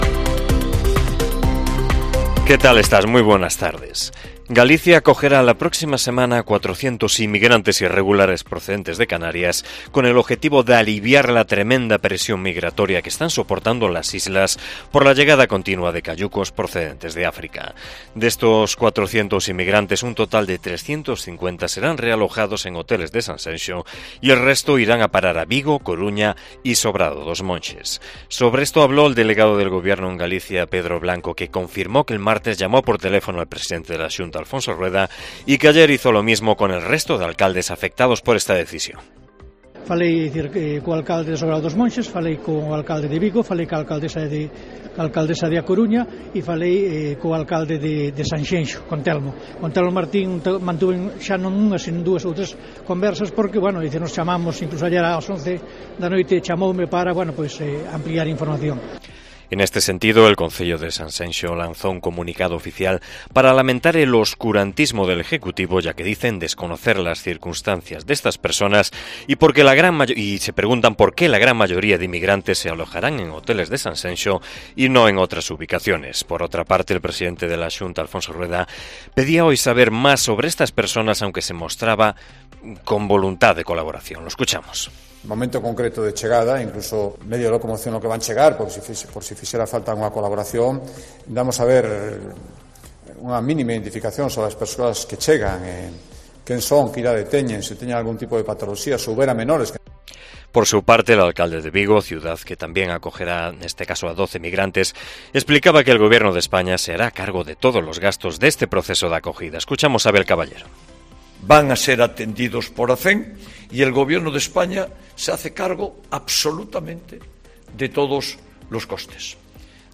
AUDIO: Informativo provincial